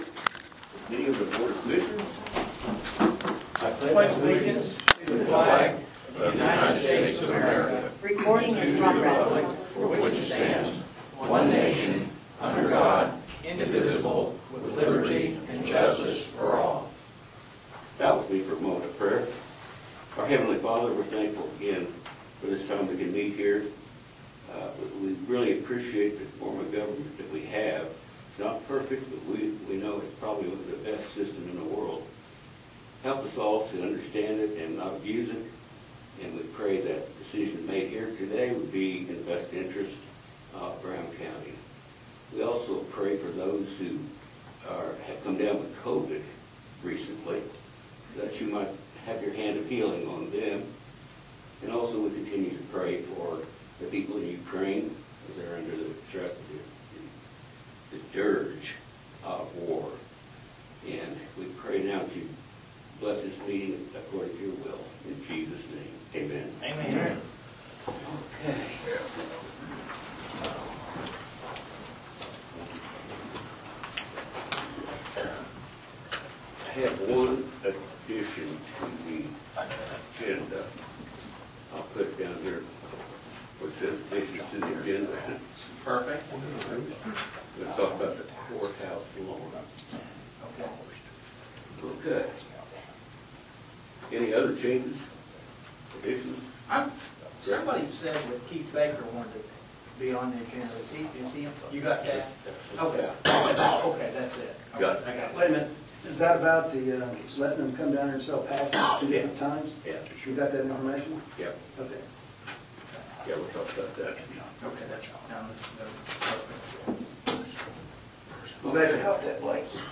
Commissioner Meeting Notes, Oct 4, 2023 Agenda Oct 4, 2023 Audio of the meeting.
Commissioner Pittman provided a good summary of the history of the plant and current challenges.